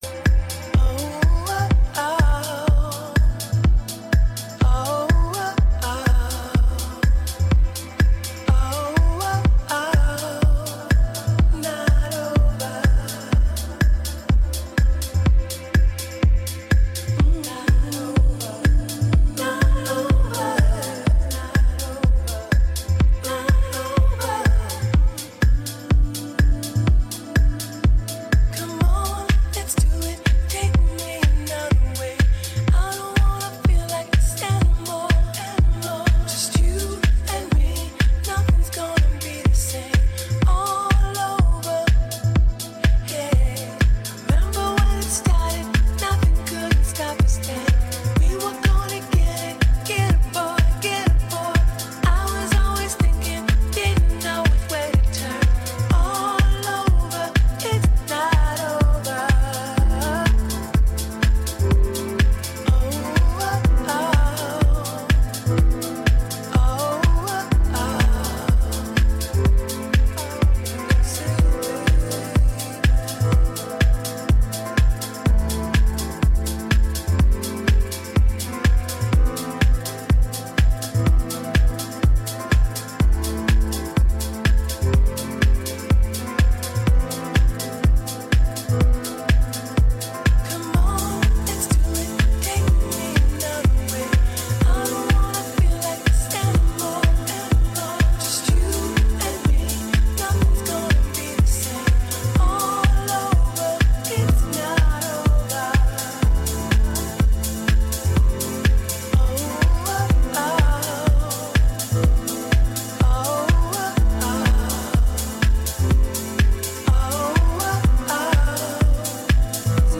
Live Wednesday through Saturday 9-1 with the Metro Best Karaoke with Mile High Karaoke on 09-Oct-25-21:02:48